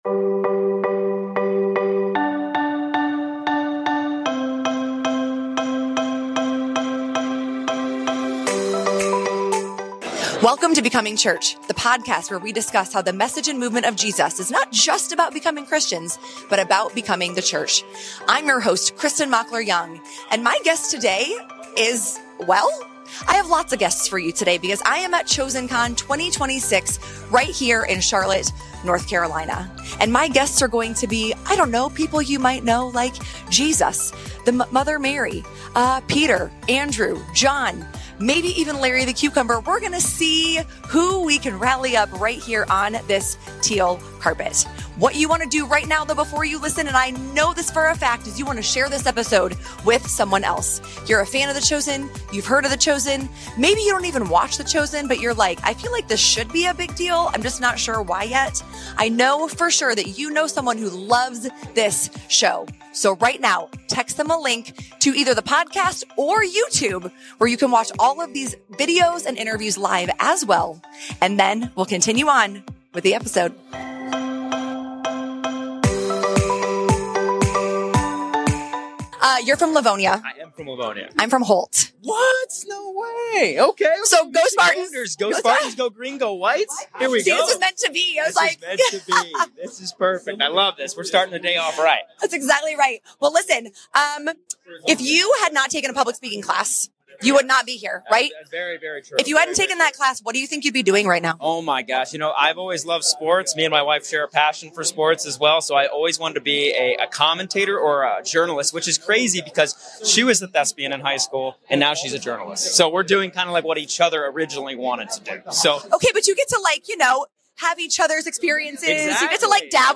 These are real conversations with the cast of The Chosen, including Jonathan Roumie, Paras Patel, and creator Dallas Jenkins.